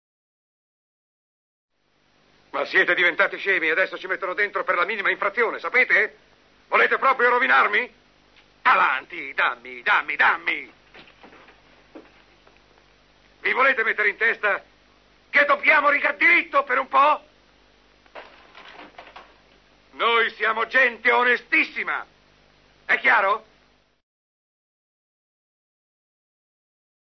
voce di Mario Pisu nel film "Fronte del porto", in cui doppia Lee J. Cobb.